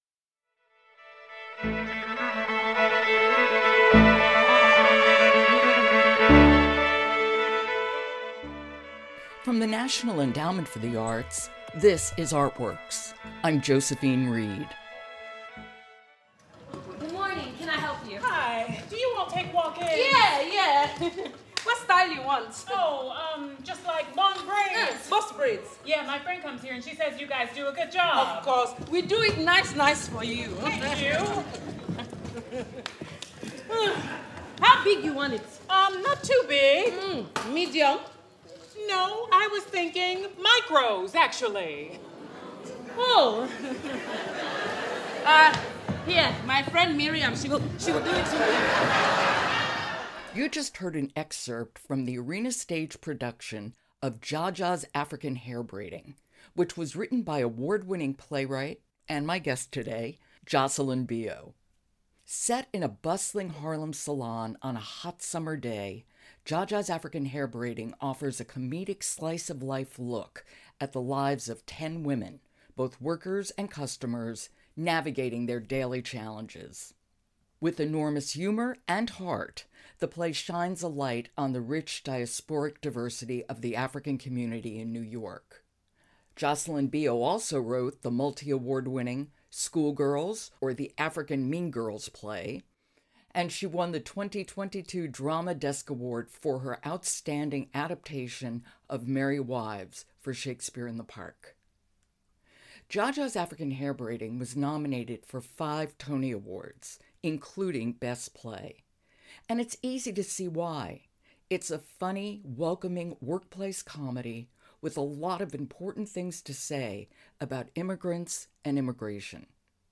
Playwright Jocelyn Bioh discusses the personal connections that inspired her award-winning play Jaja’s African Hair Braiding and her use of humor to shine a spotlight on serious issues.